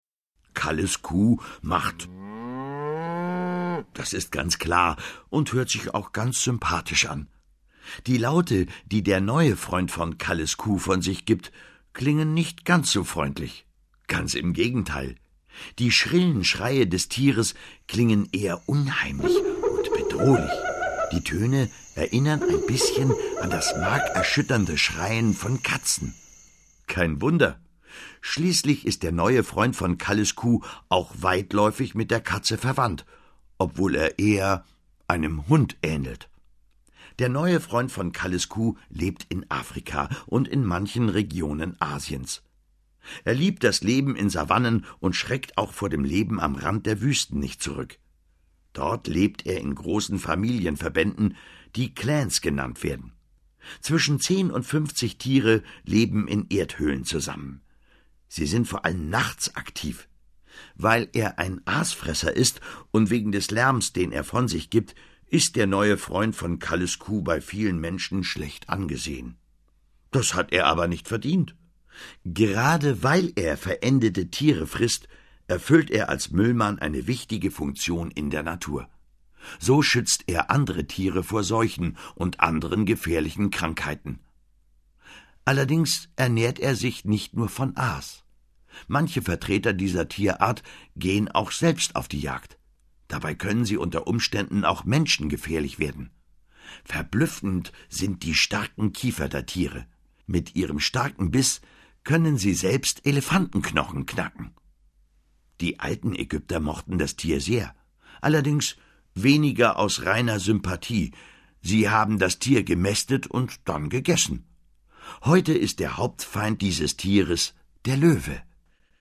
Tommi Piper (Sprecher)